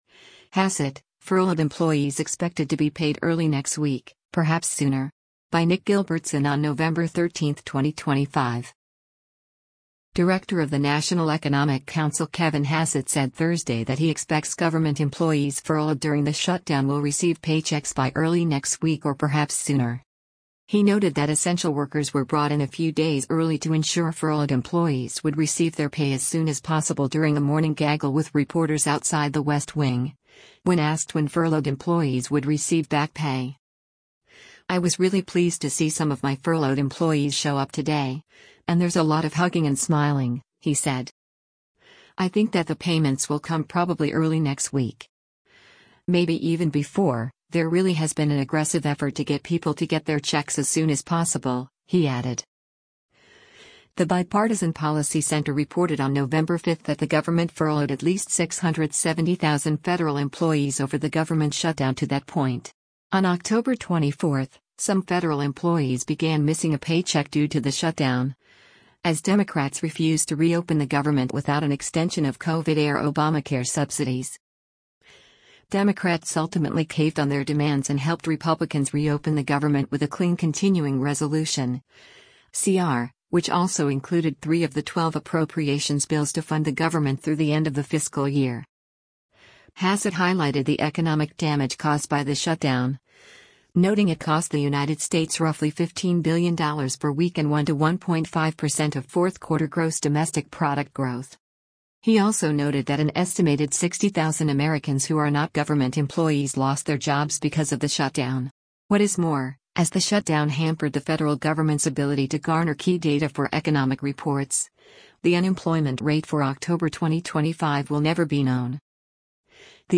He noted that essential workers were brought in a few days early to ensure furloughed employees would receive their pay “as soon as possible” during a morning gaggle with reporters outside the West Wing, when asked when furloughed employees would receive back pay.